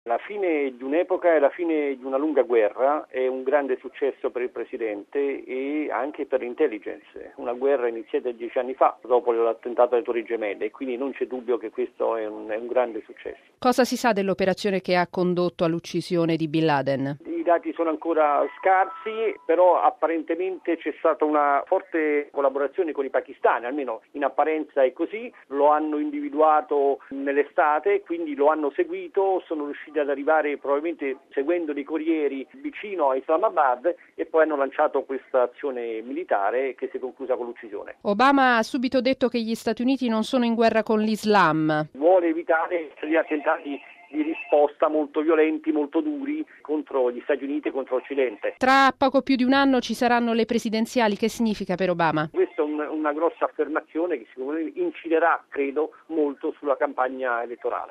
ha raggiunto telefonicamente a Washington